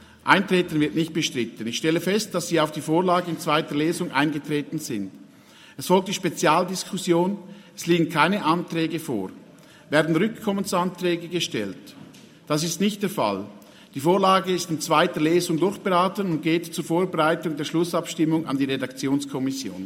12.6.2023Wortmeldung
Session des Kantonsrates vom 12. bis 14. Juni 2023, Sommersession